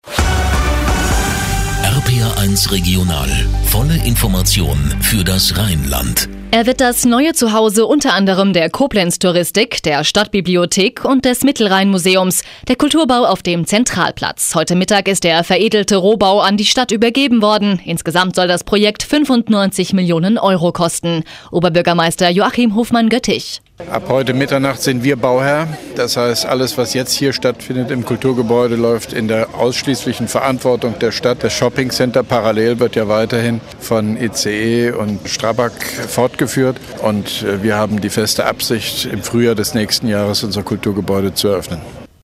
Ausschnitt: RPR1 Regional, Informationen für das Rheinland, Studio Koblenz, 10.05.2012
Mit Kurzinterview von OB Hofmann-Göttig